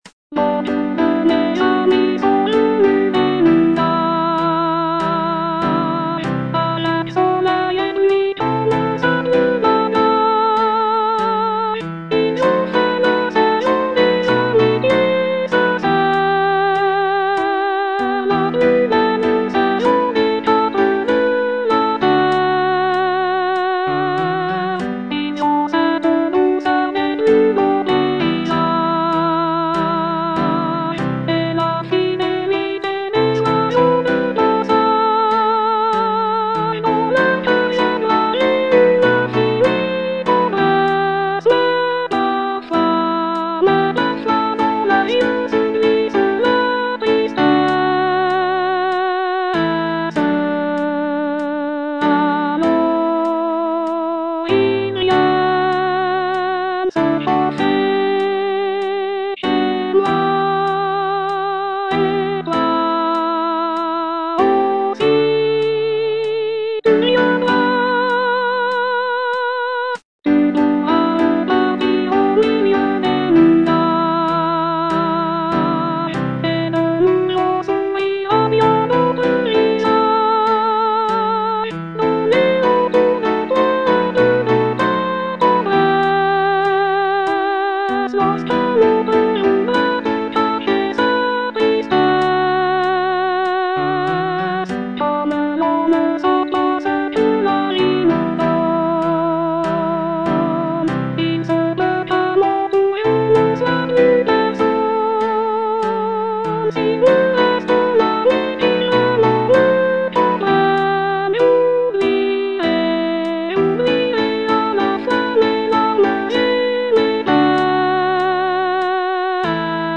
Soprano (Voice with metronome)
piece for choir